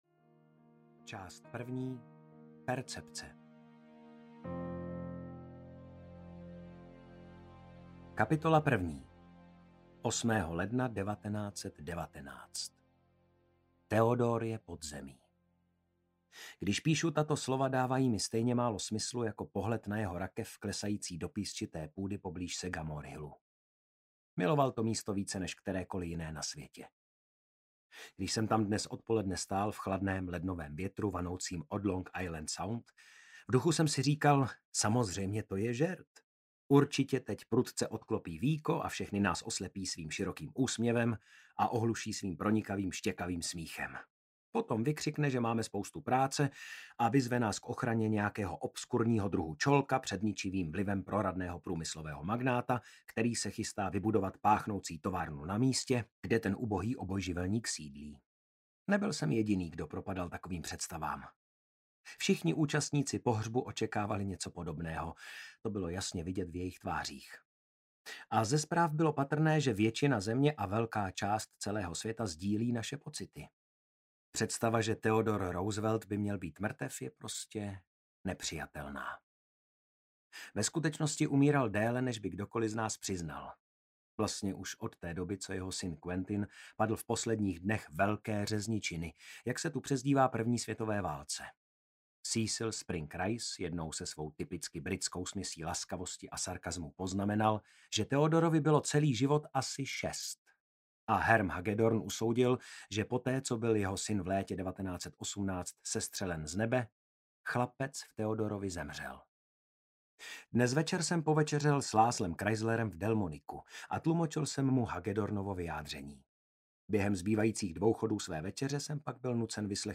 Ukázka z knihy
psychiatr-audiokniha